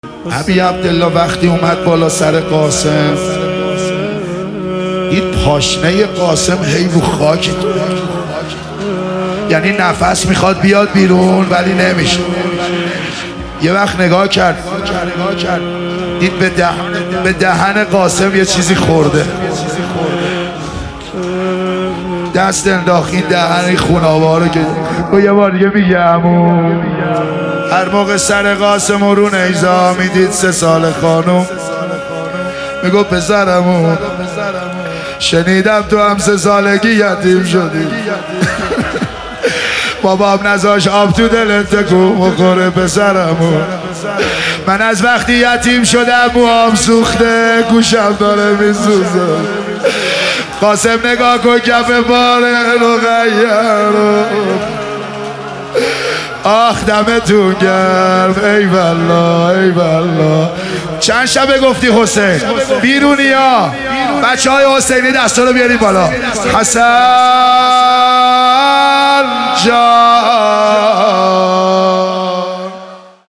محرم 1400 | حسینیه کربلا اندرزگو تهران